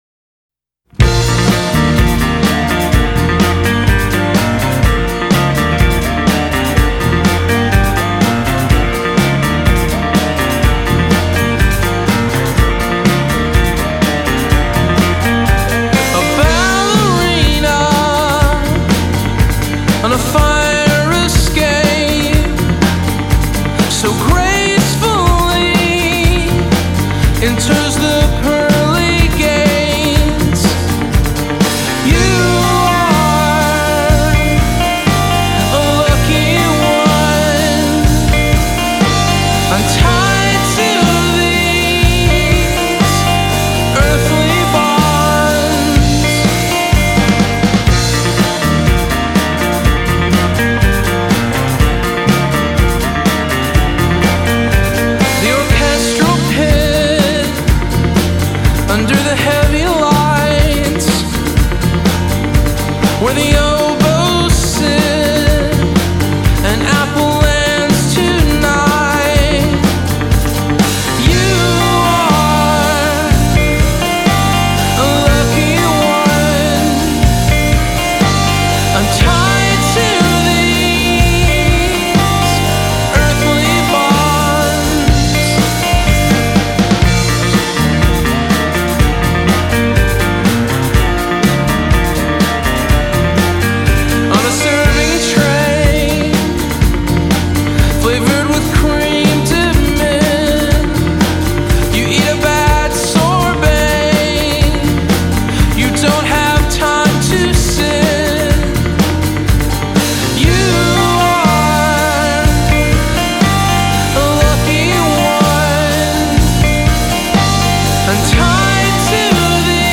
gtr, vox
bass
drums
(keyboards).